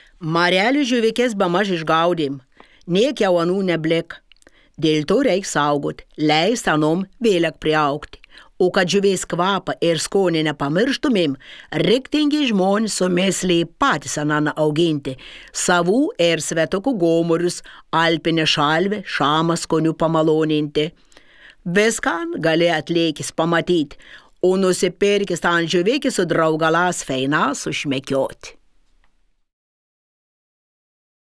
Pasiklausyk šišioniškai